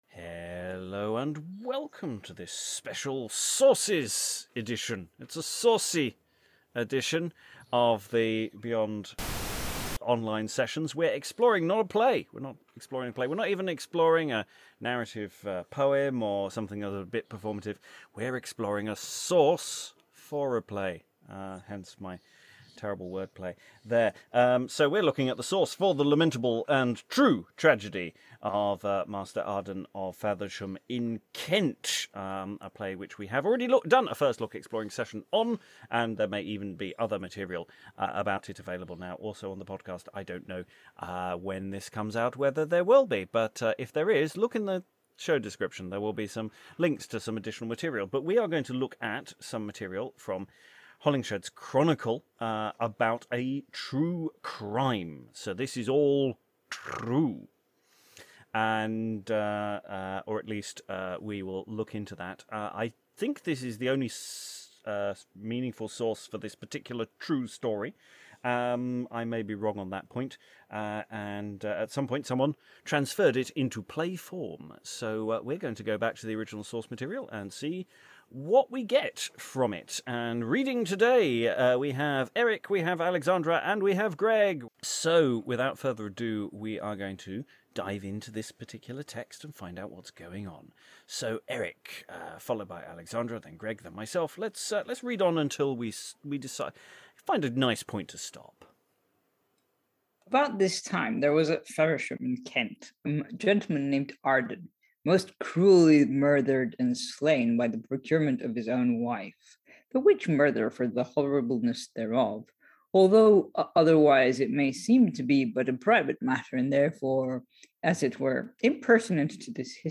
This prelude to the series proper is a read through and discussion of the play's source material from Holinshed's Chronicles of England, Scotland and Ireland.